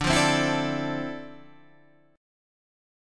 D Minor 9th